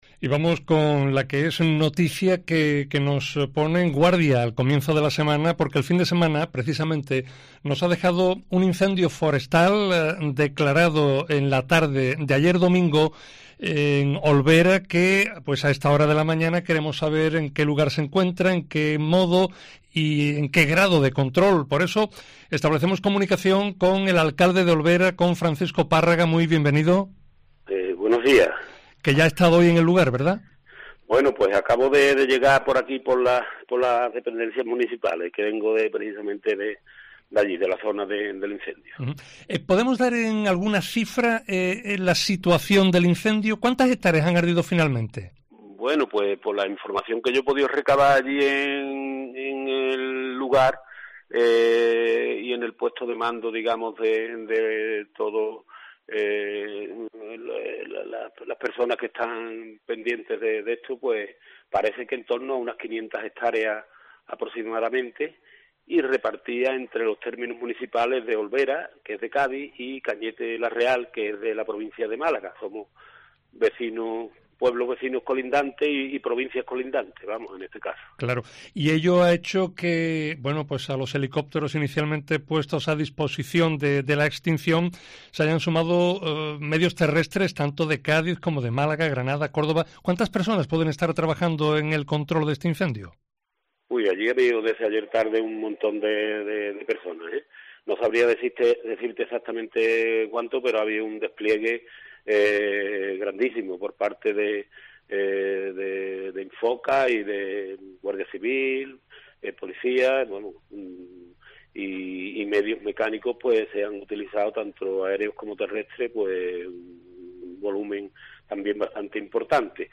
Entrevista con Francisco Párraga, alcalde de Olvera, sobre incendio forestal